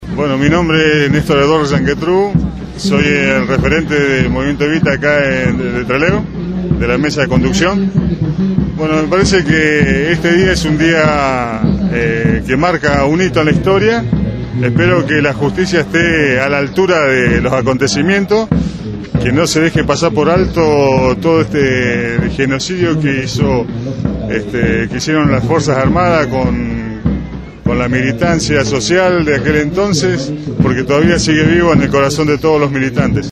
La sentencia se conoció en el centro Cultural Municipal «José Hernández», de Rawson.
Los enviados especiales de Radio Gráfica recopilaron los testimonios de los mismos.